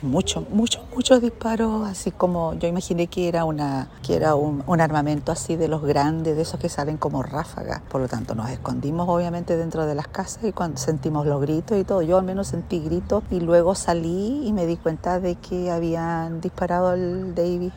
Otra mujer relató que debieron esconderse mientras escuchaban gritos.